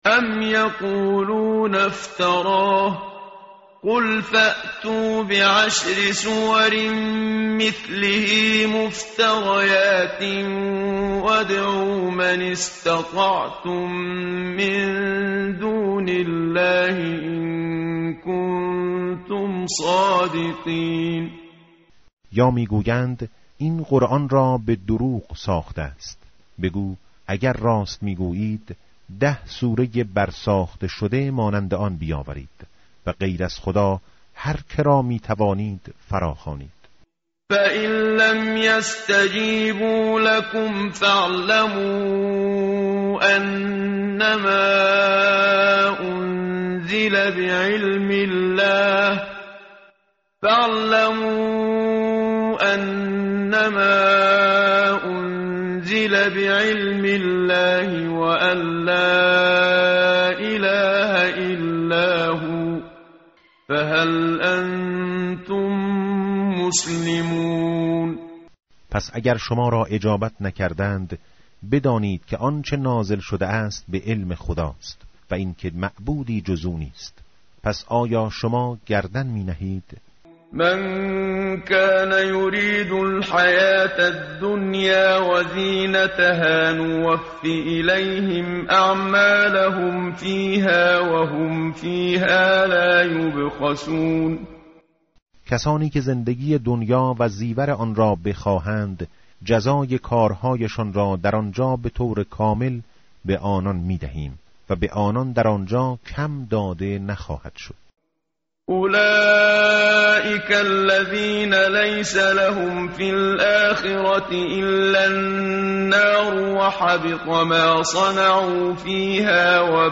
متن قرآن همراه باتلاوت قرآن و ترجمه
tartil_menshavi va tarjome_Page_223.mp3